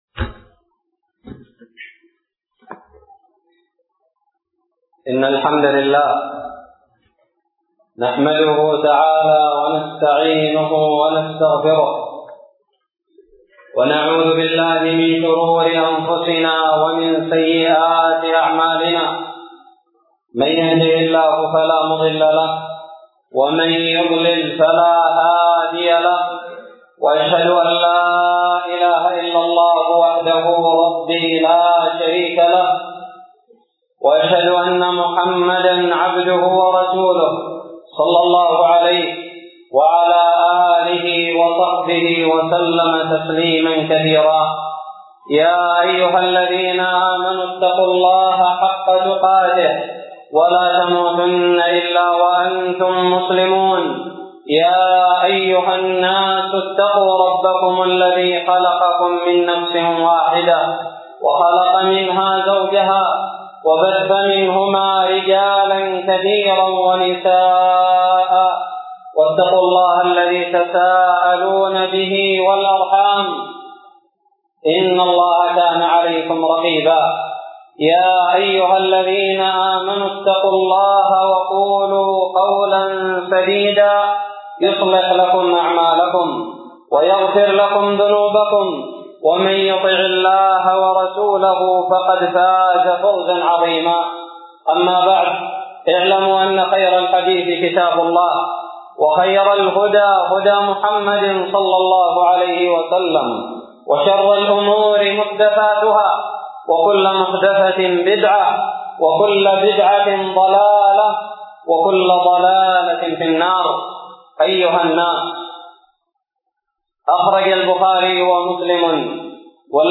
خطب الجمعة
ألقيت بدار الحديث السلفية للعلوم الشرعية بالضالع في 20 شعبان 1437هــ